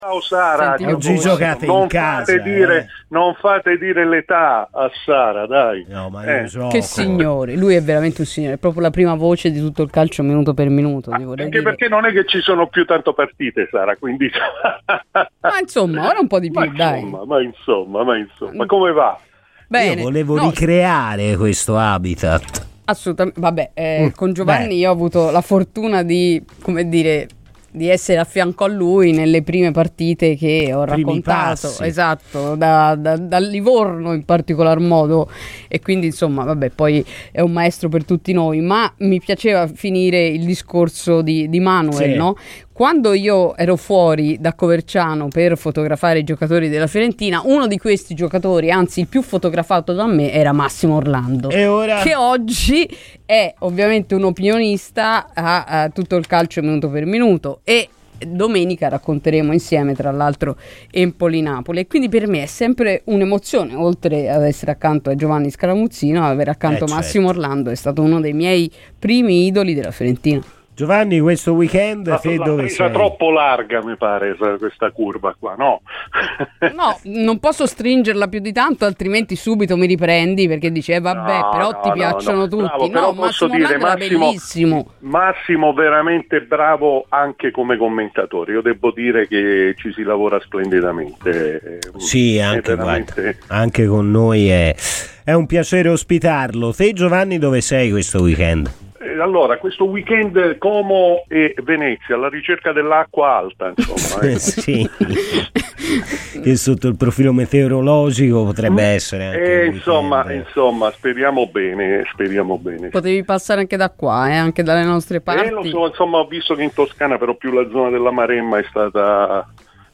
ASCOLTA IL PODCAST PER L'INTERVENTO INTEGRALE